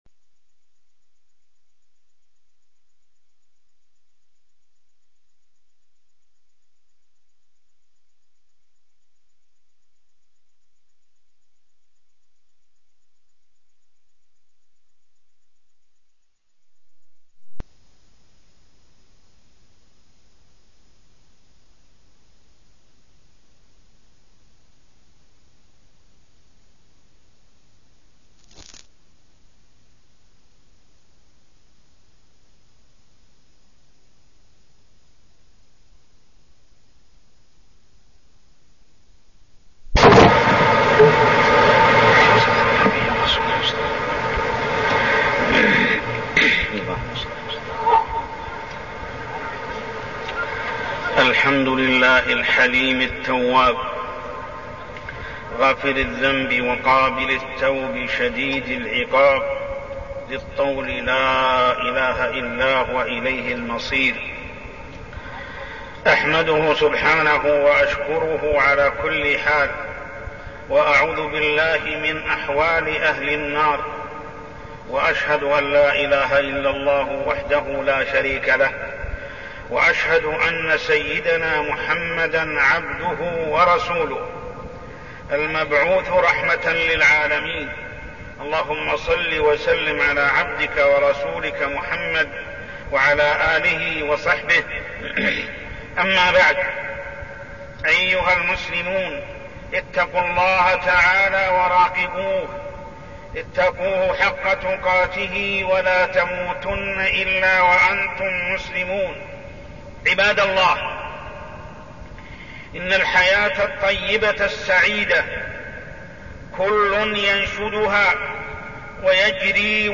تاريخ النشر ٢ صفر ١٤١٣ هـ المكان: المسجد الحرام الشيخ: محمد بن عبد الله السبيل محمد بن عبد الله السبيل حقيقة السعادة